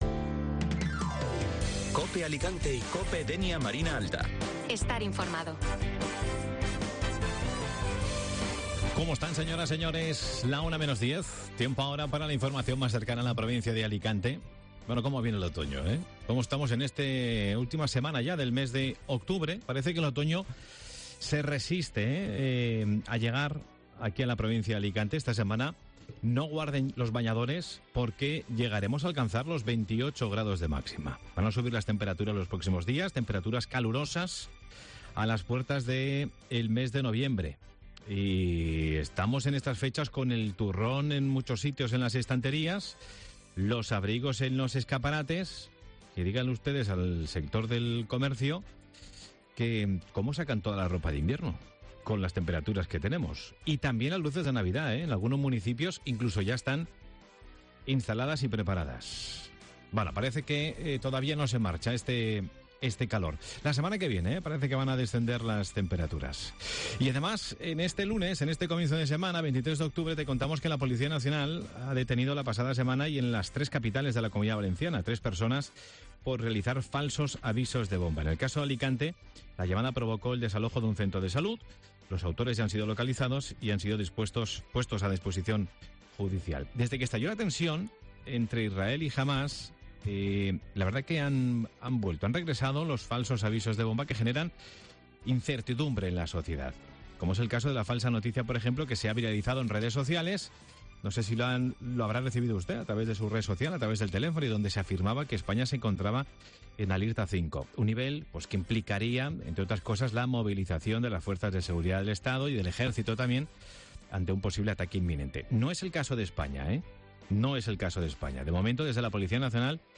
Espacio magazine que se emite de lunes a viernes de 12:50h a 13:00h y de 13:50h a 14:00h con entrevistas y actualidad de la provincia de Alicante.